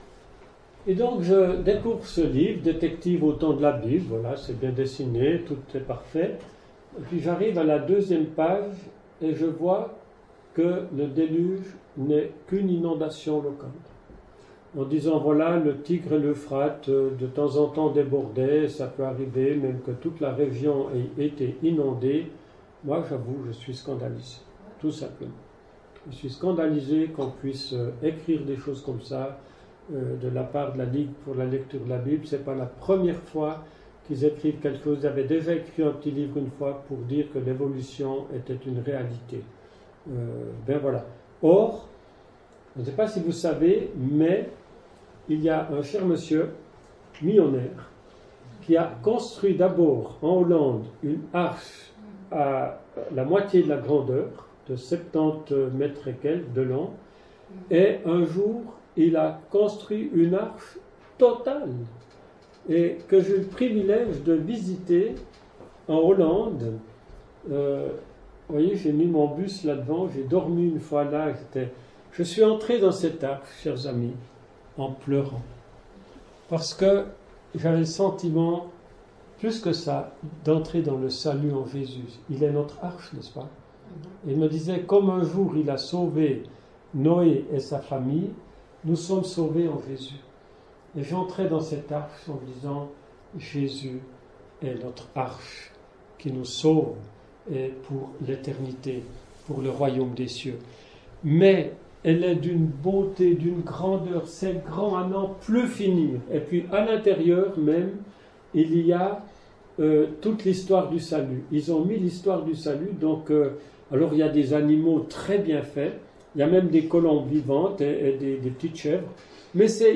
[Chapelle de l’Espoir] - Conférence "Science et Foi", deuxième partie